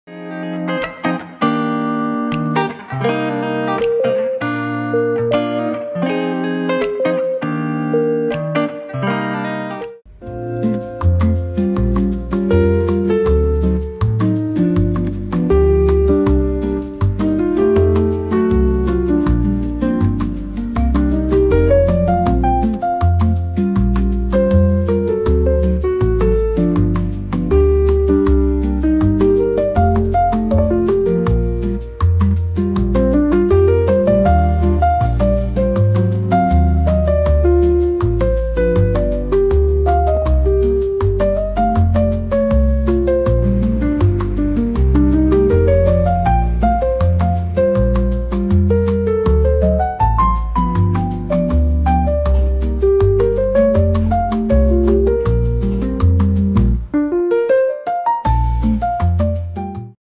aquascape